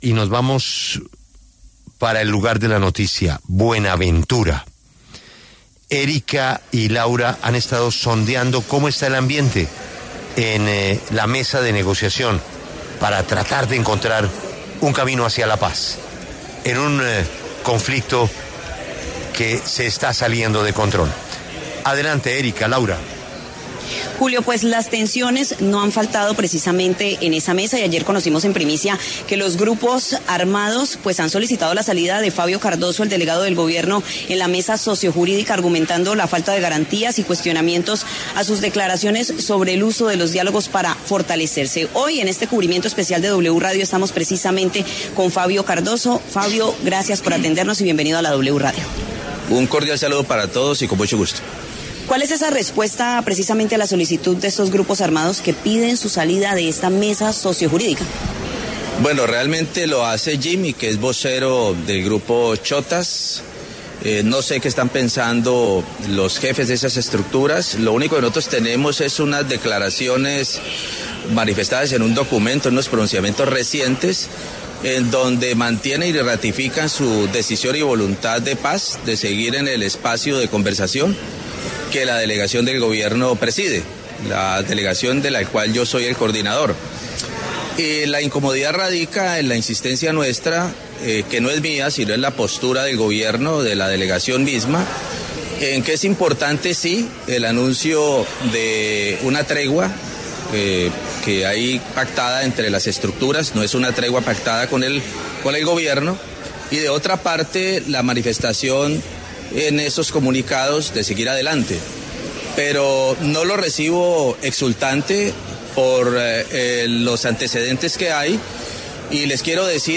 Fabio Cardozo, jefe de la delegación del Gobierno en los diálogos entre ‘Shottas’ y ‘Espartanos’ en Buenaventura, respondió en La W a quienes piden su salida de la mesa.